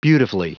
Prononciation du mot beautifully en anglais (fichier audio)